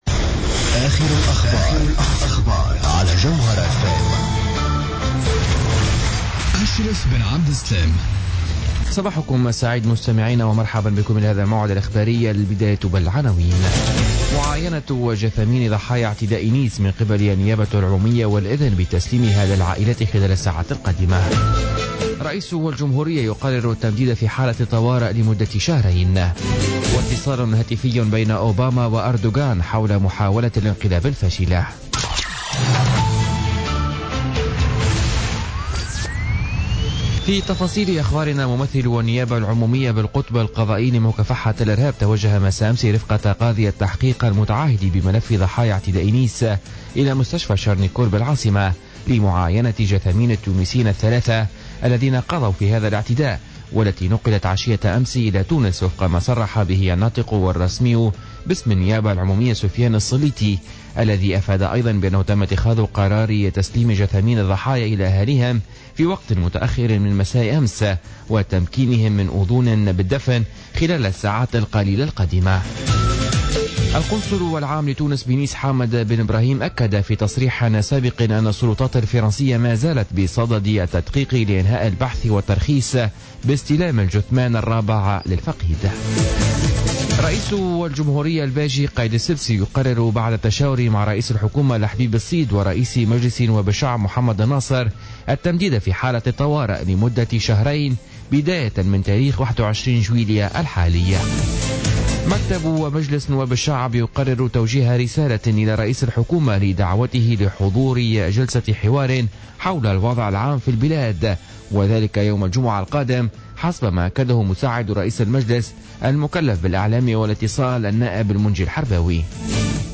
نشرة أخبار السابعة صباحا ليوم الأربعاء 20 جويلية 2016